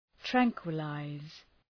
Προφορά
{‘træŋkwə,laız}